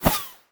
bullet_flyby_fast_13.wav